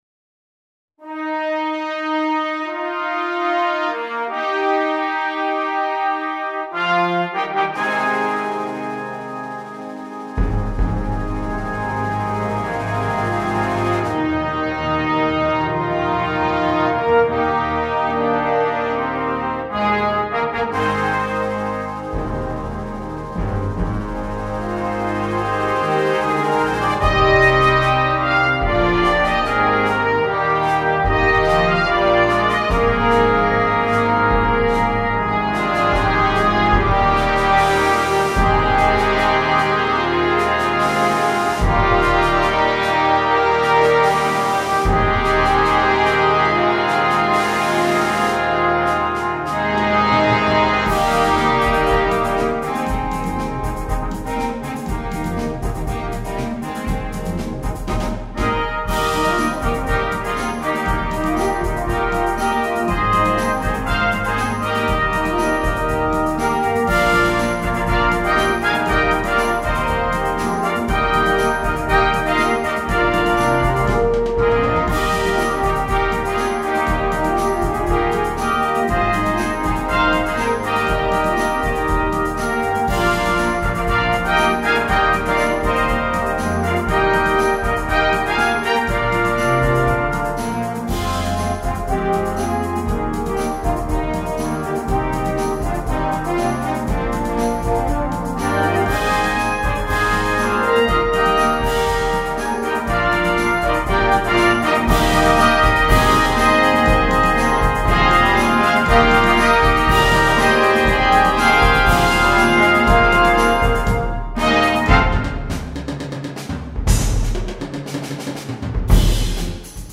für Blasorchester
Besetzung: Blasorchester